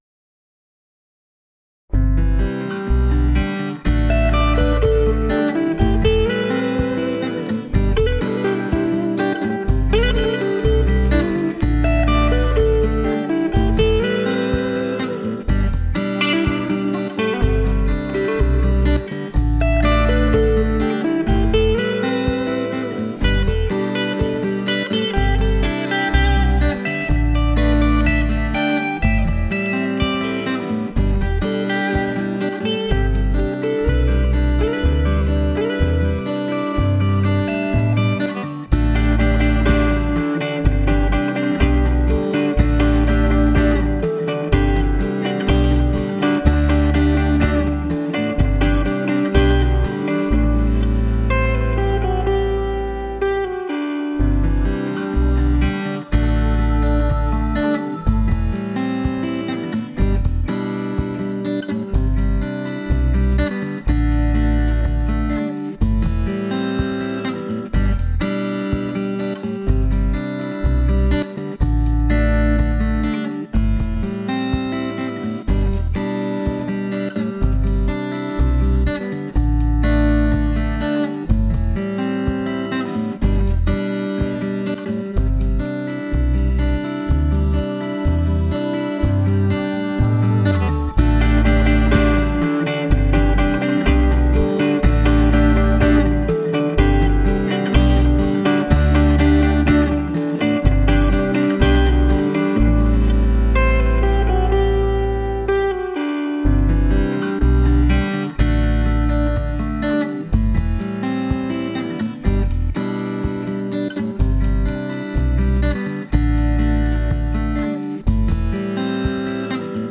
Instrumental version
BPM 124